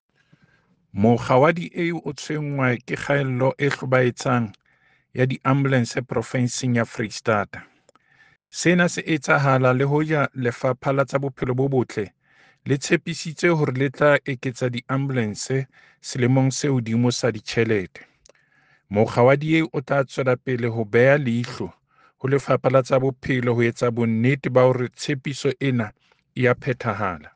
Sesotho soundbites by David Masoeu MPL.